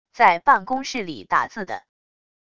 在办公室里打字的wav音频